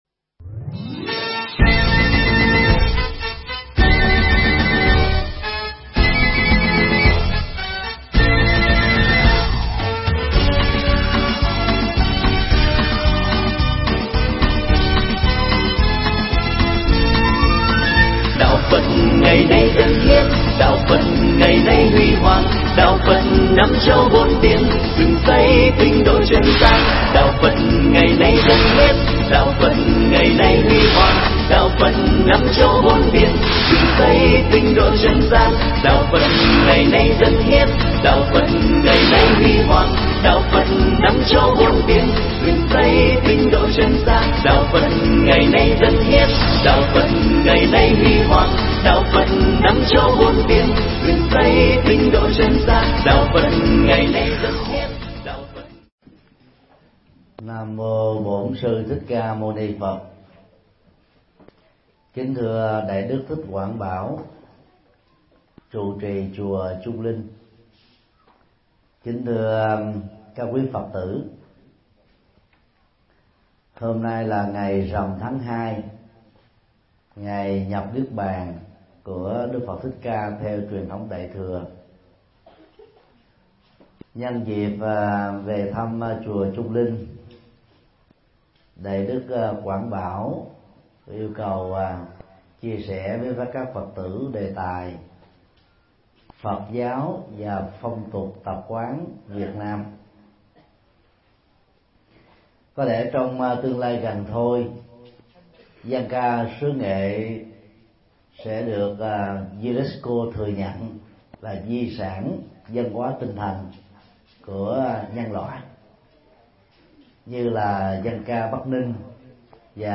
Mp3 Pháp Thoại Phật giáo và phong tục tập quán Việt Nam – Thầy Thích Nhật Từ Giảng tại chùa Chung Linh, Nghệ An, ngày 15 tháng 3 năm 2014